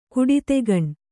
♪ kuḍitegaṇ